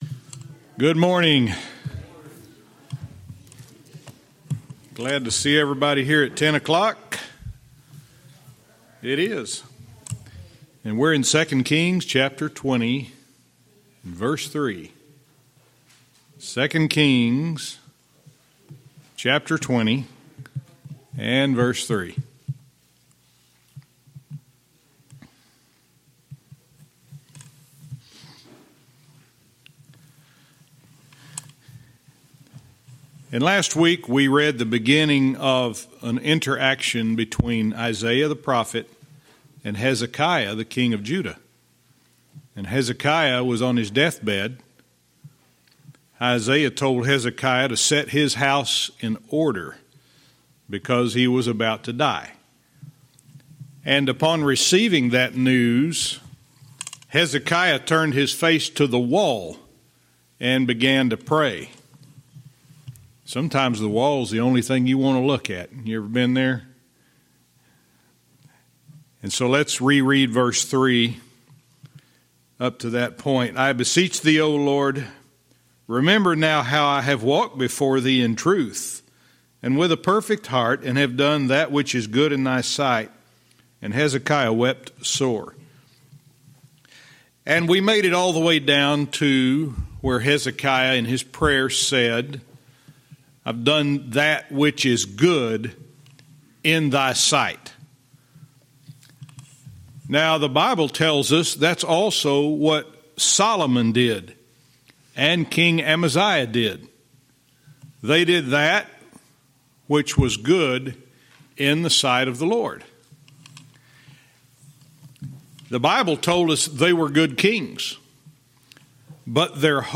Verse by verse teaching - 2 Kings 20:3(cont)-6